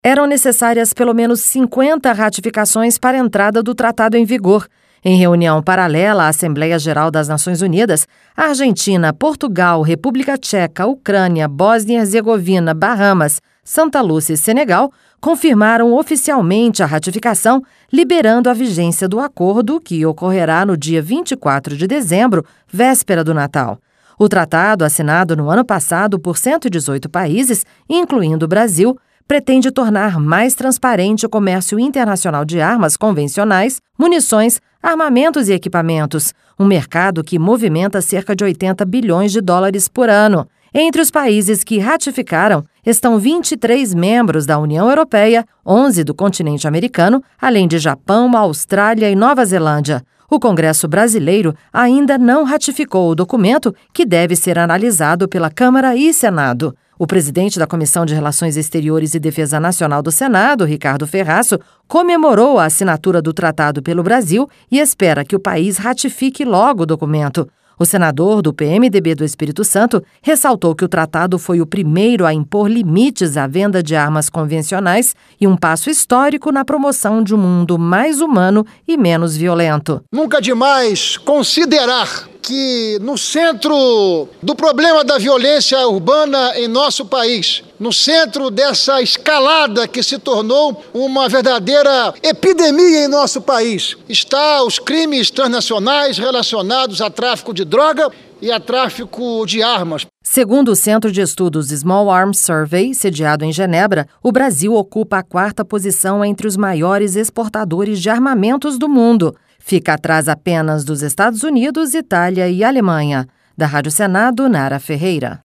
O presidente da Comissão de Relações Exteriores e Defesa Nacional, Ricardo Ferraço, comemorou a assinatura do tratado pelo Brasil e espera que o País ratifique logo o documento.